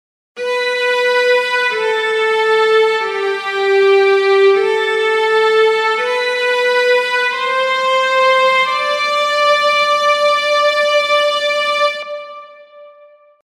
Звуки грустной скрипки
Не мем, но скрипка звучит печально